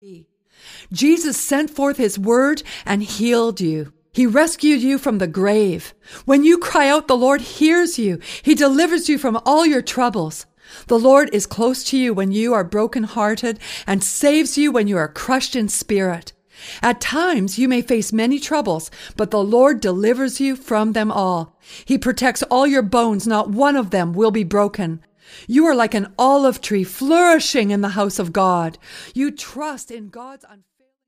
Music CD